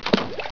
Splash
SPLASH.WAV